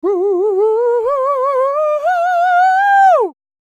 DD FALSET012.wav